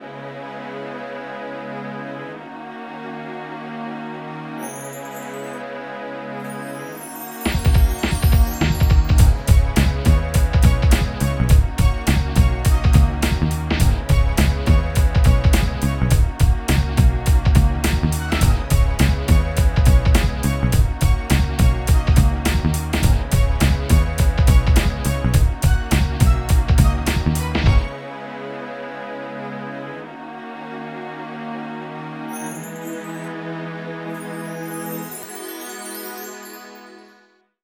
14 LOOP   -L.wav